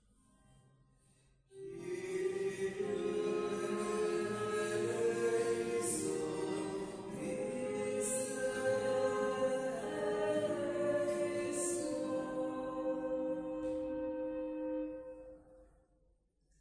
Pregària de Taizé a Mataró... des de febrer de 2001
Església del Sagrat Cor - Diumenge 29 de maig de 2022